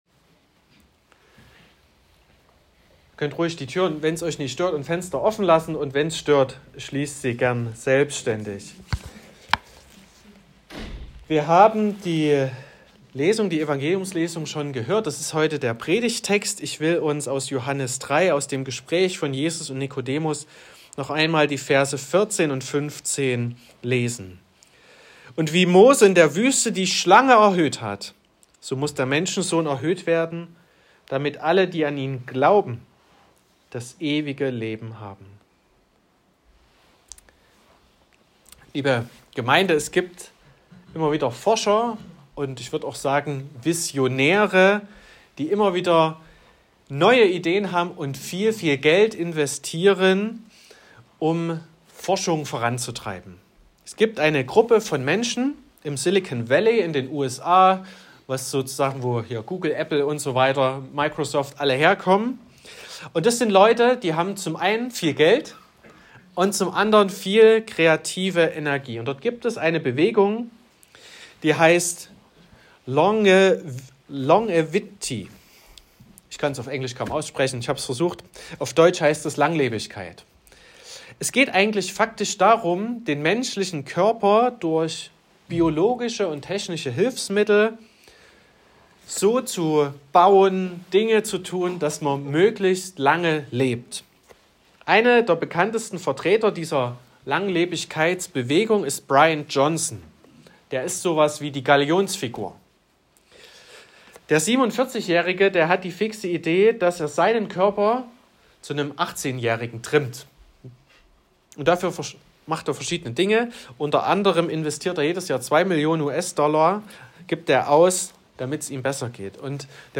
16.03.2025 – Gottesdienst
Predigt (Audio): 2025-03-16_Schlangengift_und_Kreuzesgnade-_was_uns_wirklich_rettet.m4a (9,1 MB)